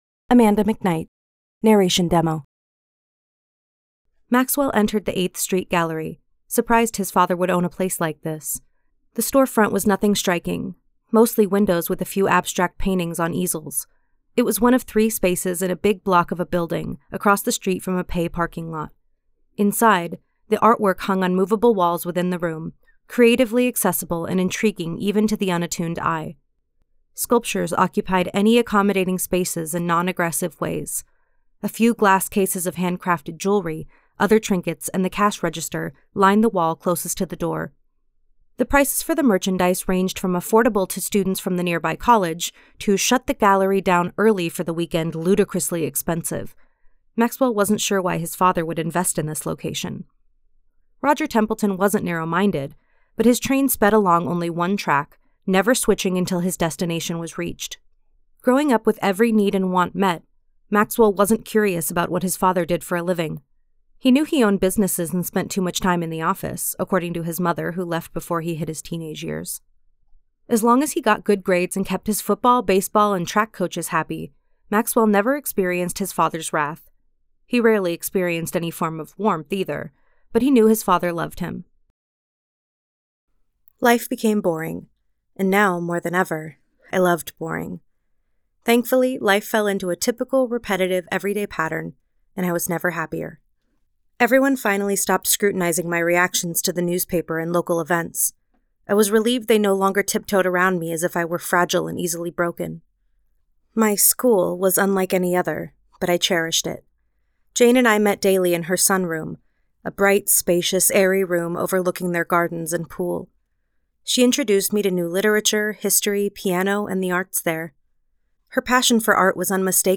Audio Book Voice Over Narrators
Yng Adult (18-29) | Adult (30-50)